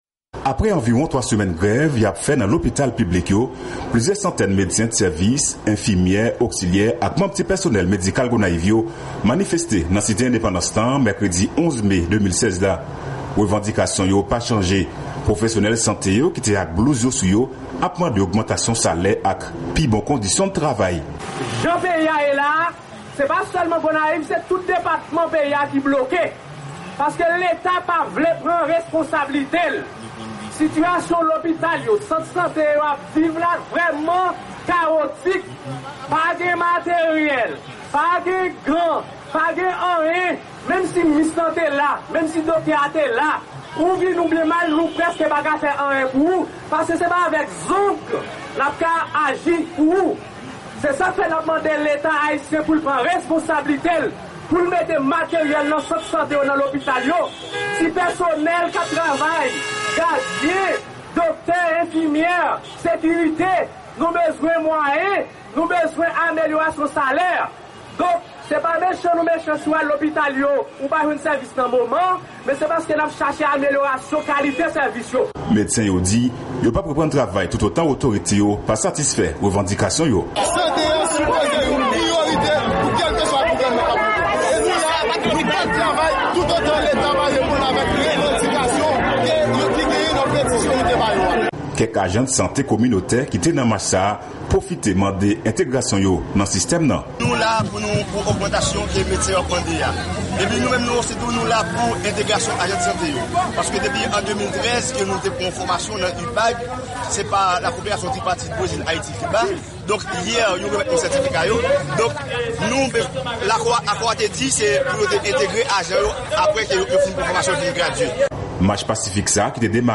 Korespondan Sèvis Kreyòl la